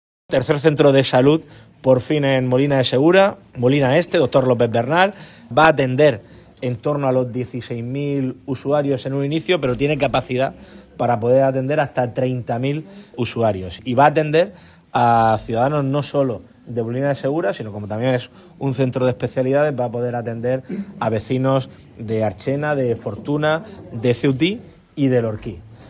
Declaraciones del presidente López Miras sobre el nuevo centro de salud de Molina de Segura, que ha sido inaugurado hoy.